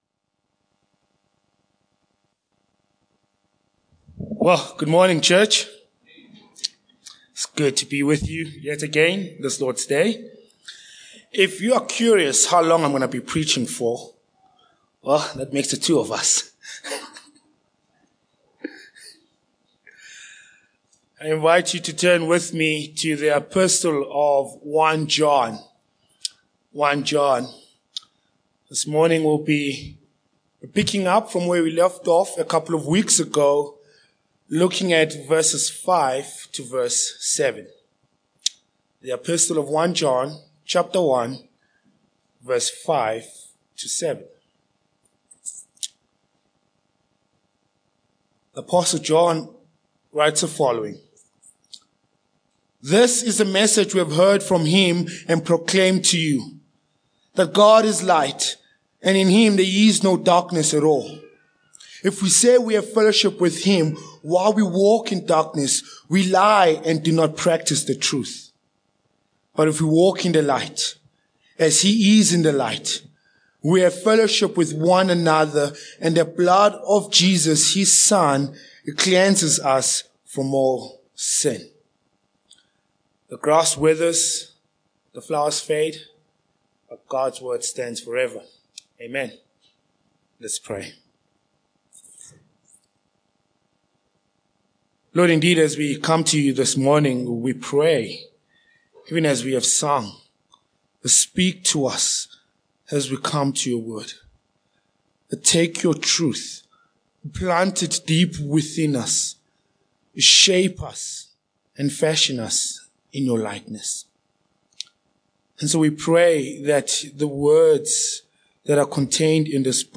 Sermon Points 1.